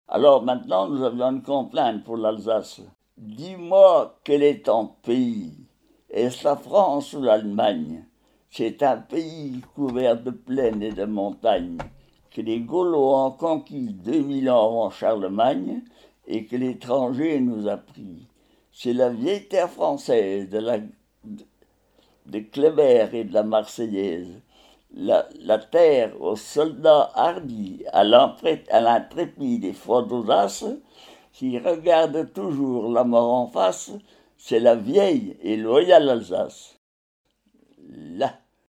Genre récit
témoignages et quelques chansons
Catégorie Récit